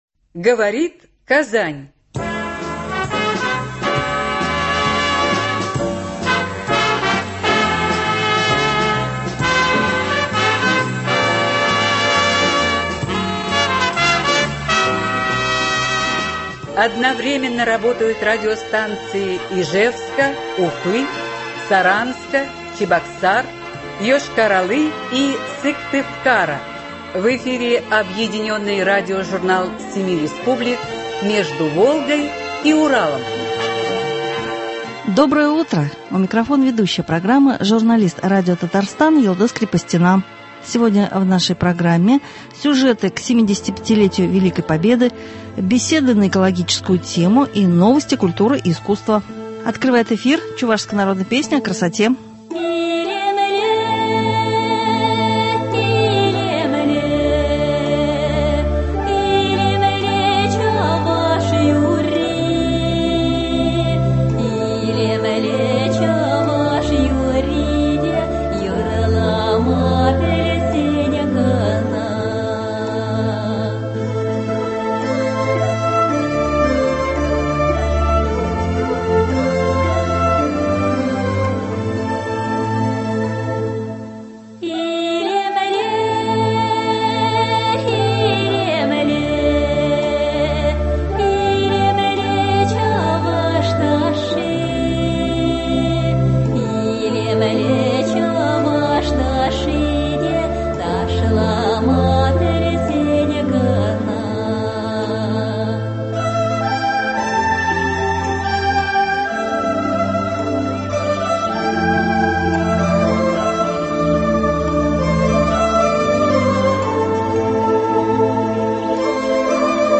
Объединенный радиожурнал семи республик.